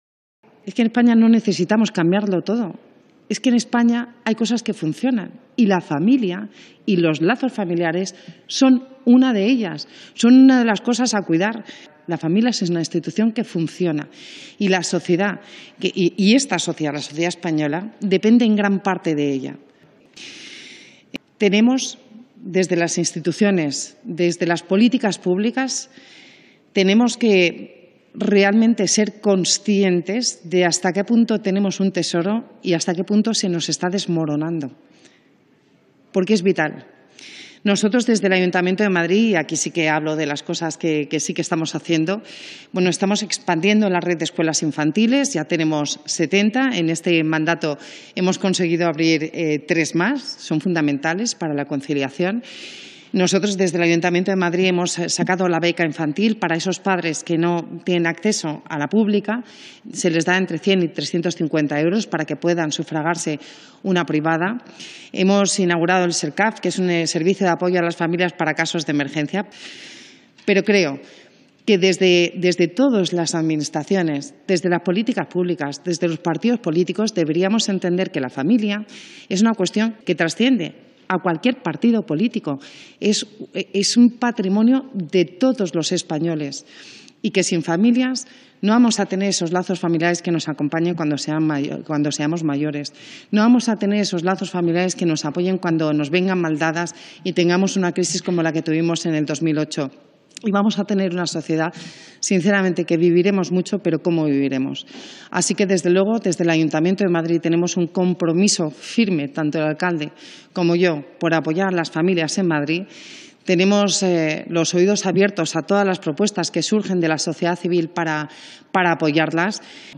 La vicealcaldesa, Begoña Villacís, ha participado hoy en el V Foro Stop Suicidio Demográfico, organizado por la Asociación de Familias Numerosas junto a la Fundación Renacimiento Demográfico y en el que el objetivo es situar a las familias numerosas como un pilar fundamental en la batalla para frenar el avance del envejecimiento demográfico y la falta de nacimientos.
BVillacis-IntervencionCongresoStopSuicidioDemografico-28-06.mp3